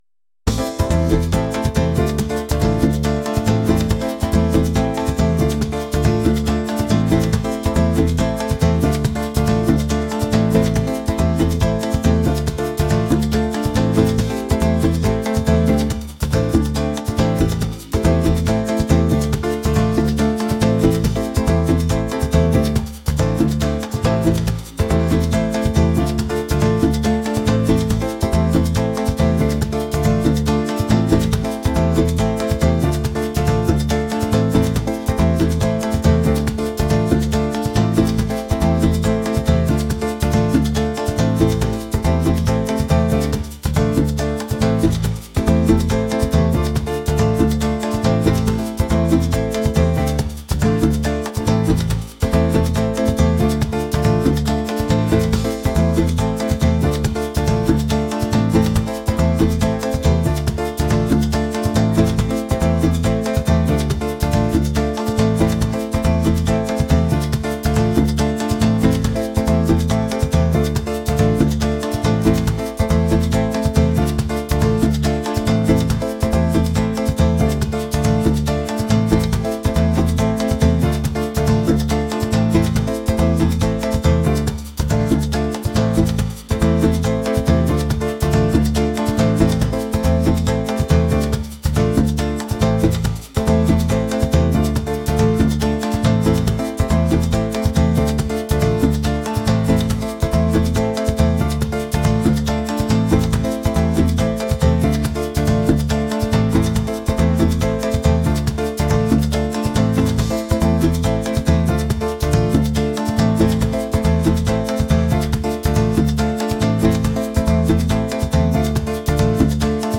latin | rhythmic